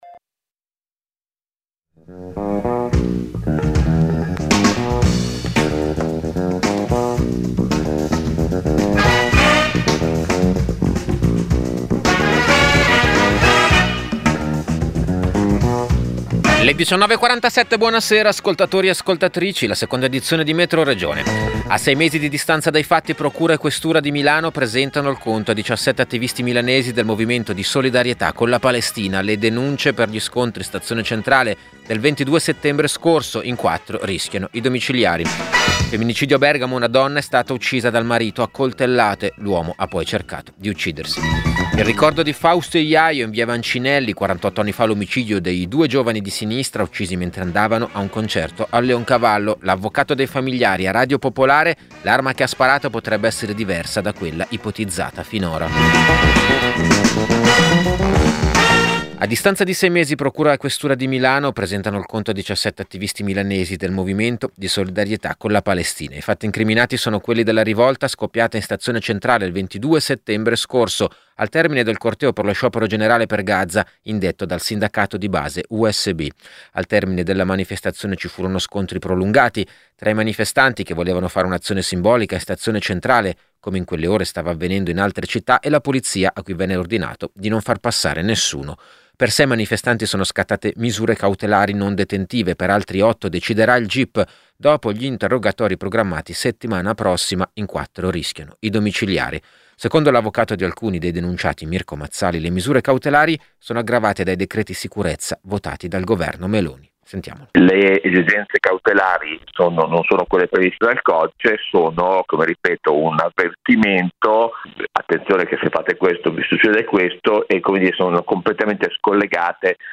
Metroregione è il notiziario regionale di Radio Popolare. Racconta le notizie che arrivano dal territorio della Lombardia, con particolare attenzione ai fatti che riguardano la politica locale, le lotte sindacali e le questioni che riguardano i nuovi cittadini.